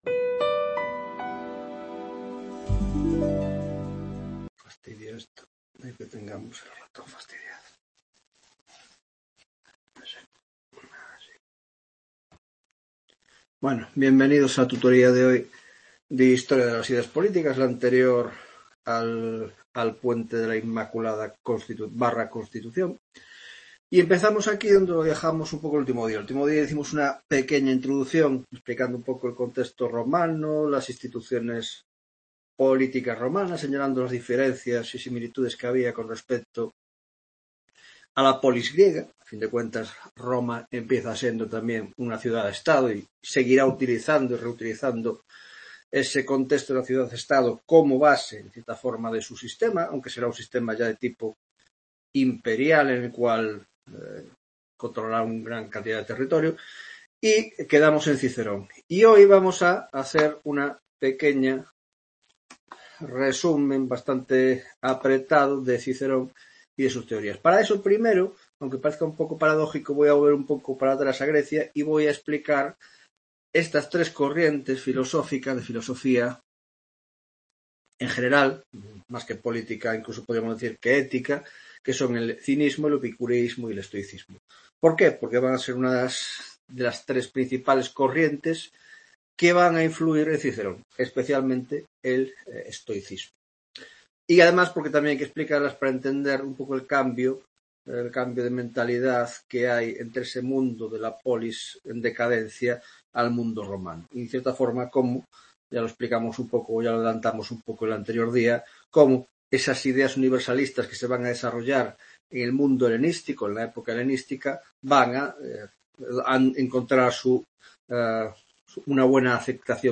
7ª Tutoria de Historia de las Ideas Políticas